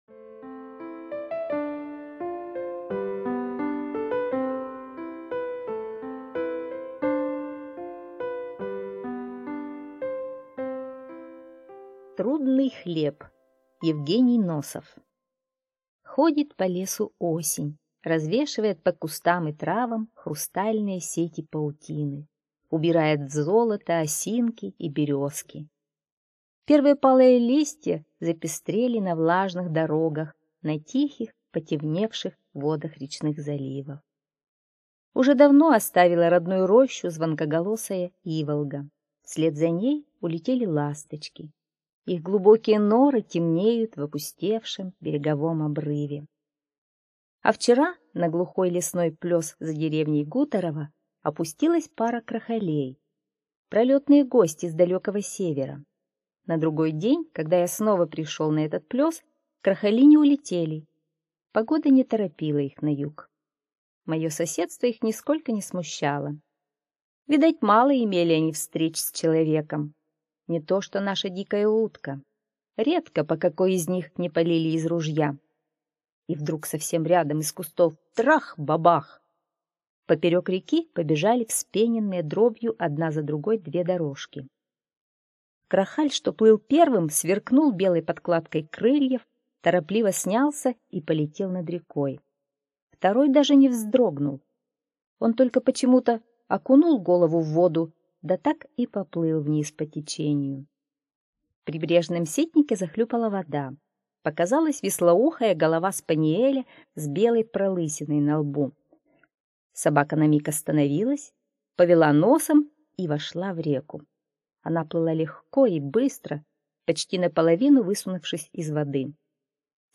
Аудиорассказ «Трудный хлеб»
книга хорошая и озвучка тоже.